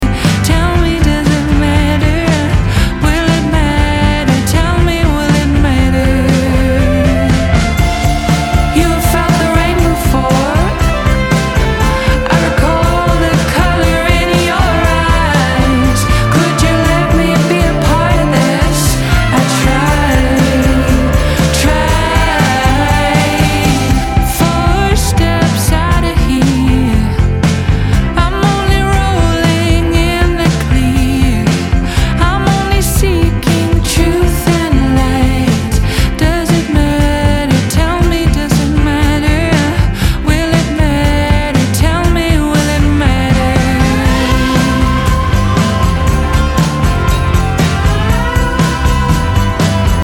Indie Rock, Indie Pop >
Folk Rock, Acoustic >